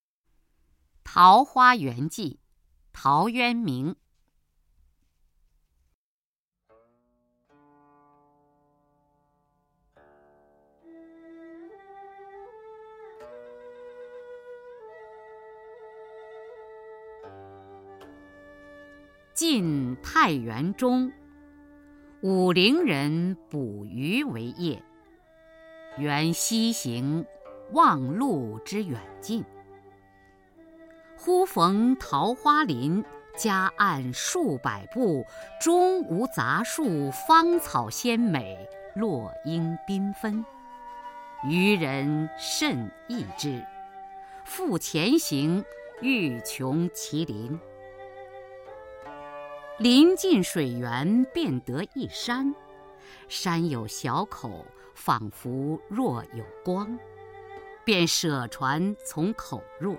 初中生必背古诗文标准朗诵（修订版）（1）-07-雅坤-桃花源记 东晋 陶渊明